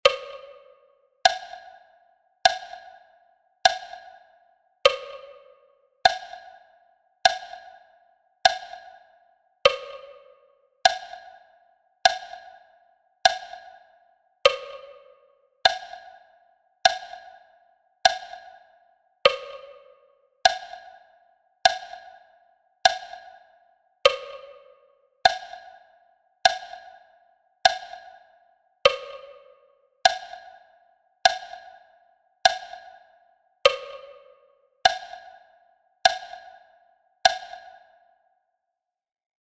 Metronome sound file
METRONOMO_para_estudiarlar_las_seis_lecciones_si_fuera_necesario.mp3